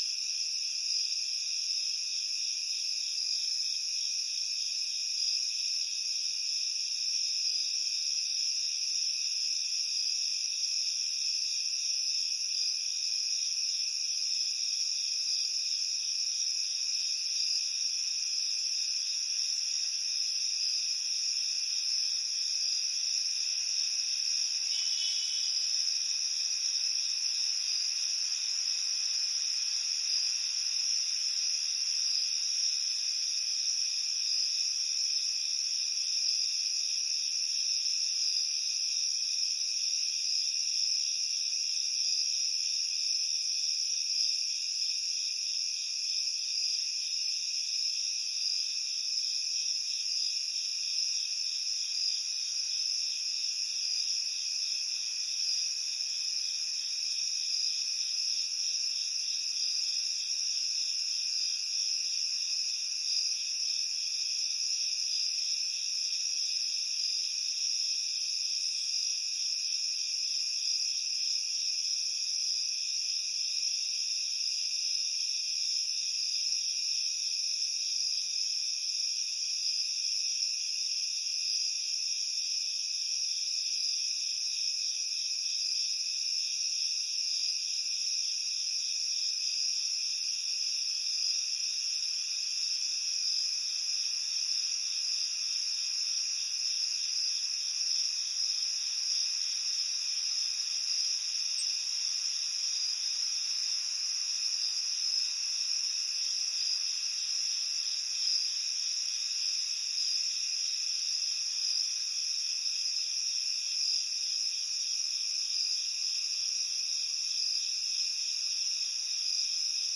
随机的 " 蟋蟀夜里的沉重后院1
Tag: 蟋蟀 后院